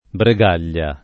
[ bre g# l’l’a ]